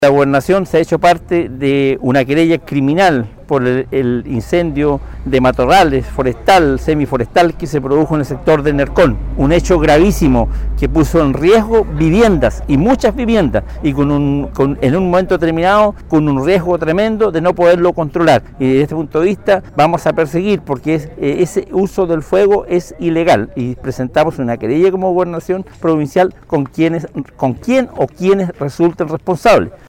Por su parte, el Gobernador de Chiloé, Pedro Andrade, confirmó que se hicieron parte de una querella criminal por el incendio que se produjo en el sector de Nercón en la comuna de Castro.